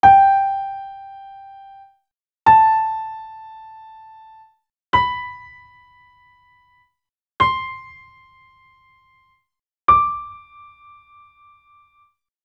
piano.wav